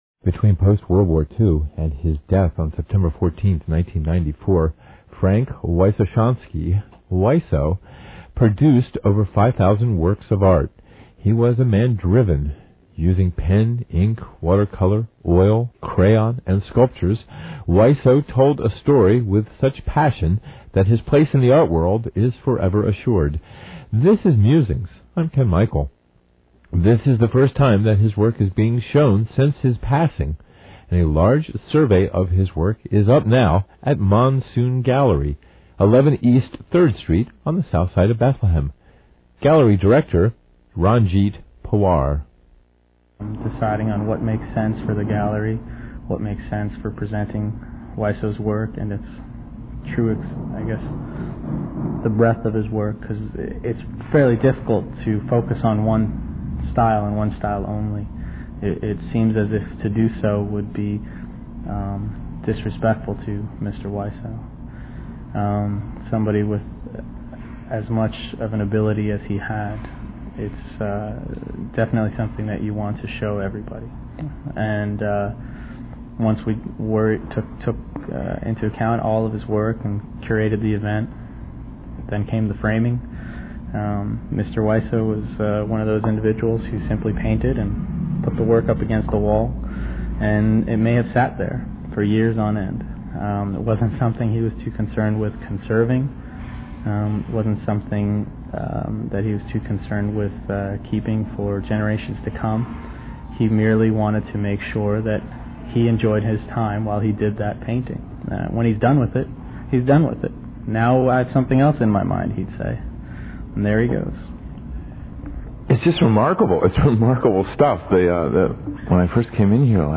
NPR Interview Part 2